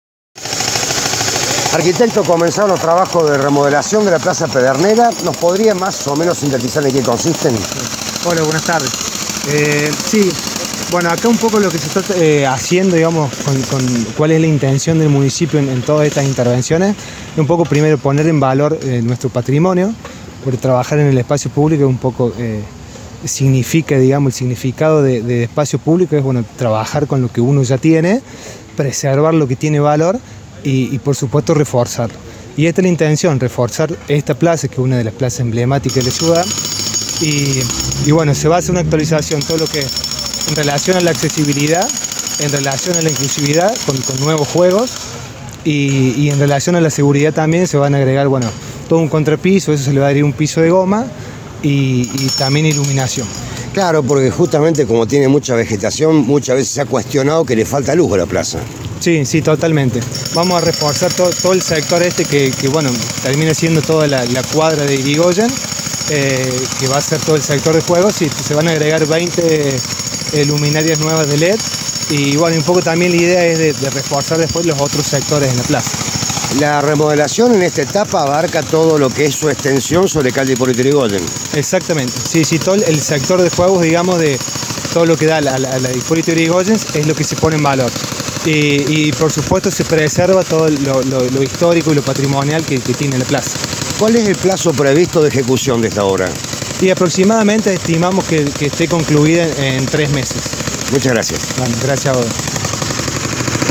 El funcionario explicó a Apuntes de San Luis cuáles son las principales características de la obra y cuáles serán las siguientes plazas públicas que se remodelarán.